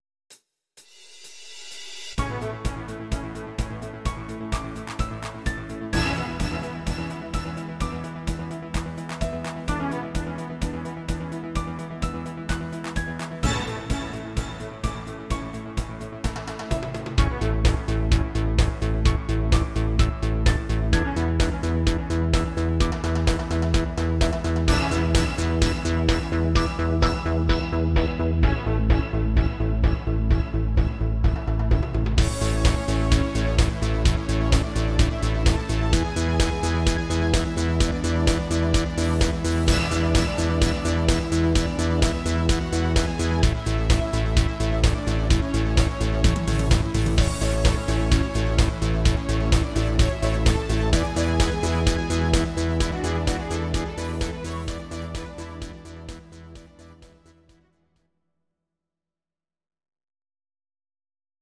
These are MP3 versions of our MIDI file catalogue.
Synthesizer